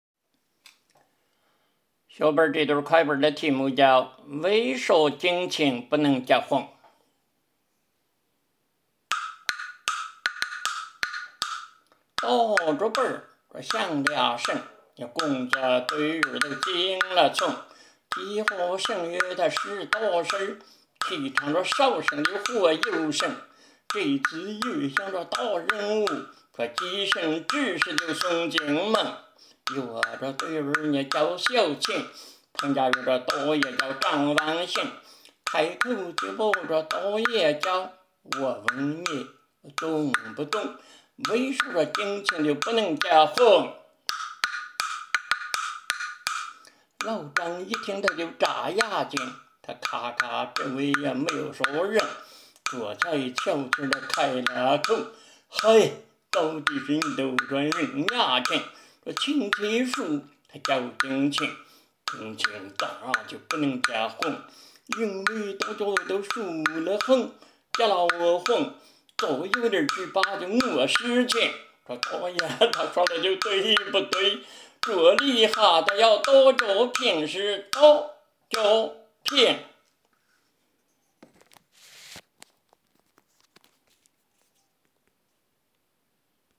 【林州方言快板】